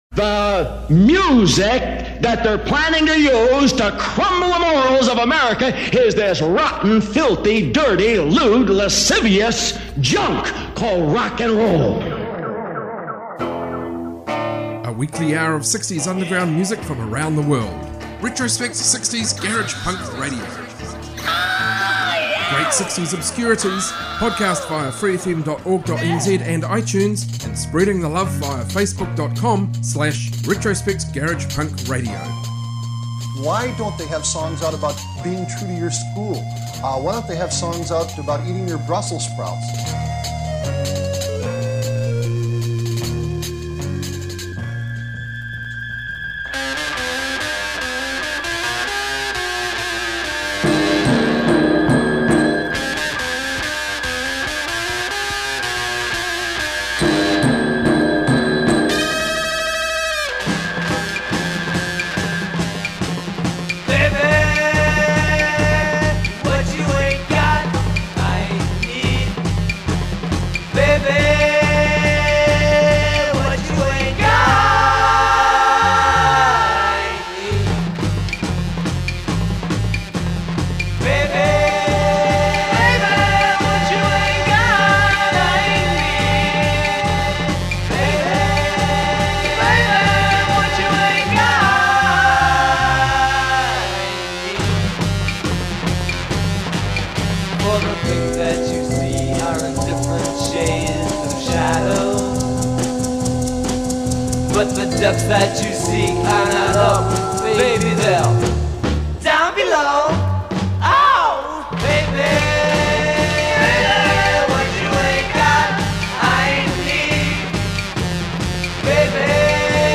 60s garage rock from all over!